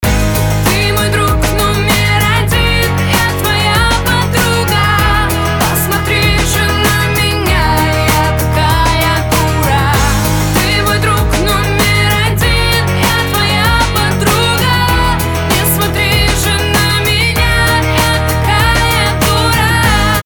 • Качество: 320, Stereo
женский вокал
русский рэп